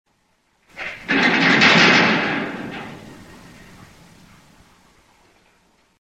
RATTLING DOOR OPENING.mp3
Original creative-commons licensed sounds for DJ's and music producers, recorded with high quality studio microphones.
rattling_door_opening_m8g.ogg